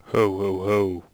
shaman_select3.wav